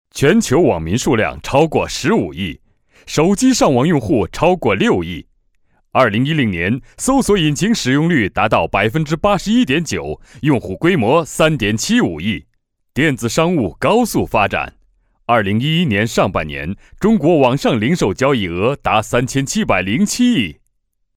I have my own home studio which can provide qualified recording and fast turn arround.
Sprechprobe: Industrie (Muttersprache):